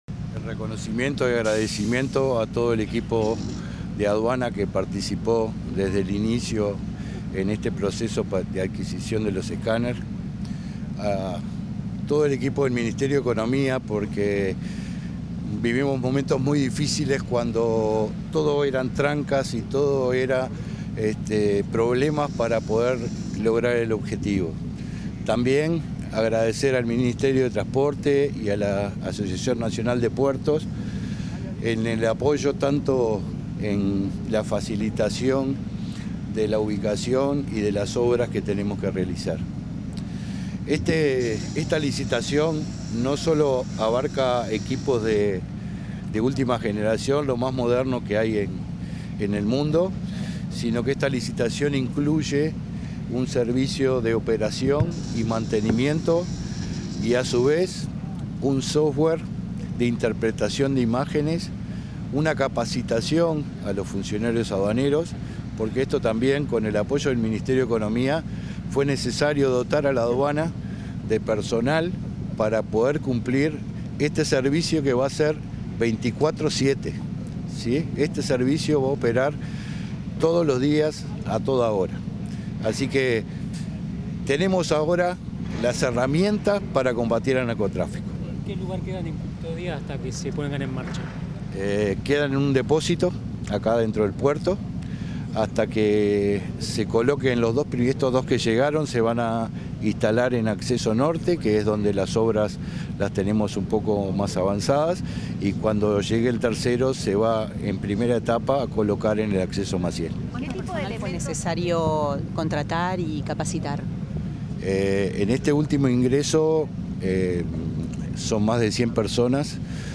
Declaraciones del director nacional de Aduanas, Jaime Borgiani